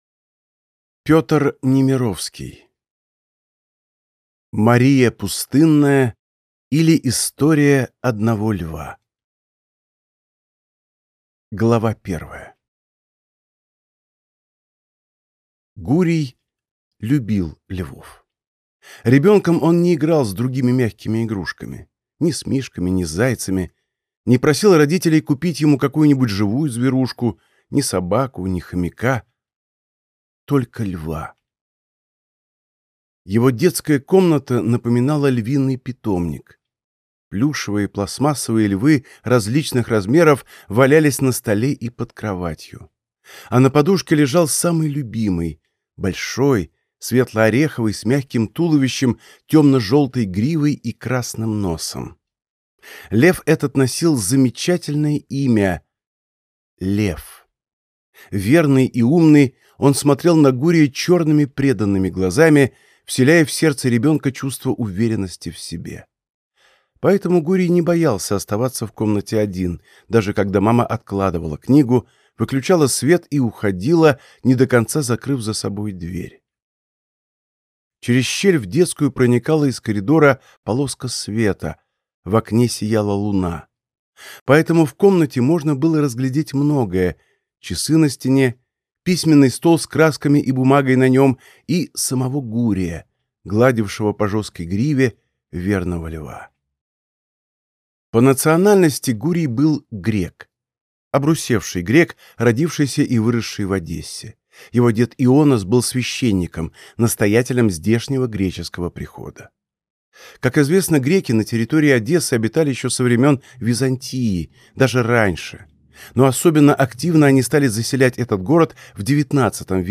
Аудиокнига Мария Пустынная, или История одного льва | Библиотека аудиокниг